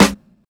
Snares
BAZ_SNR.wav